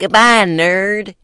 描述：我在说再见，书呆子的声音。